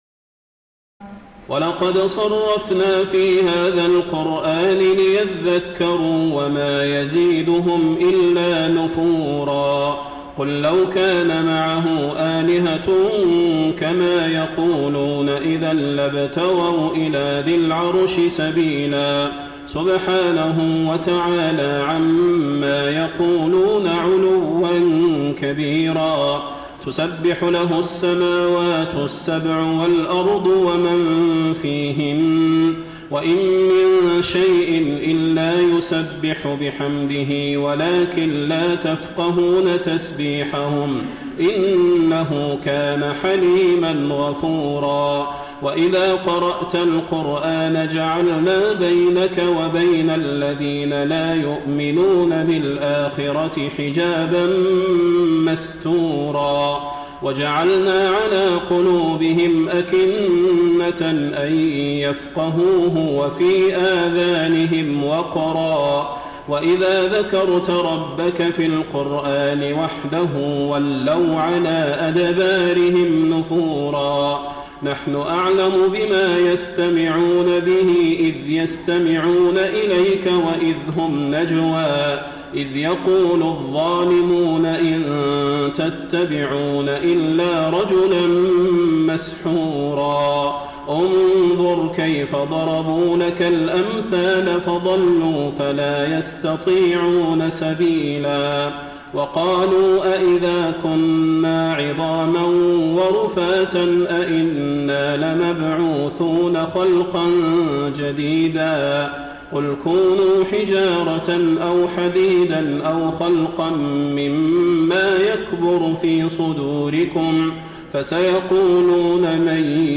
تراويح الليلة الرابعة عشر رمضان 1433هـ من سورتي الإسراء (41-111) والكهف (1-31) Taraweeh 14 st night Ramadan 1433H from Surah Al-Israa and Al-Kahf > تراويح الحرم النبوي عام 1433 🕌 > التراويح - تلاوات الحرمين